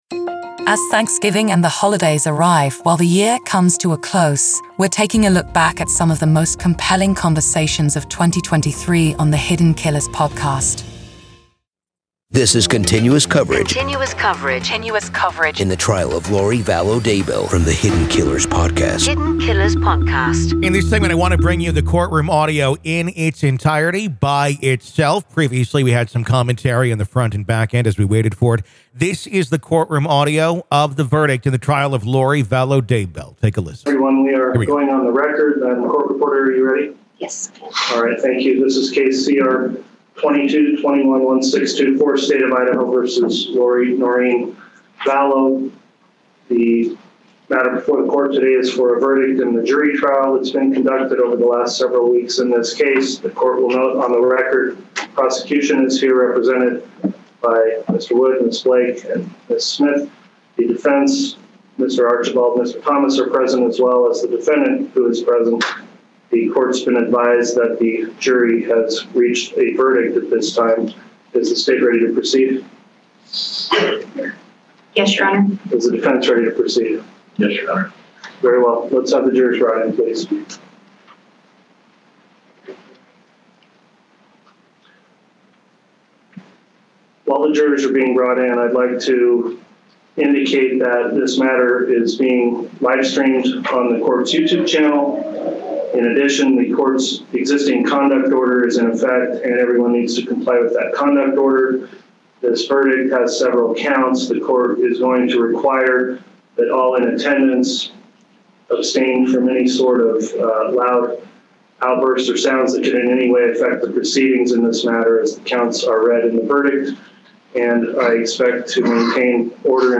Courtroom Audio-Listen To The Verdict In The Trial Of Lori Vallow Daybell- 2023 IN REVIEW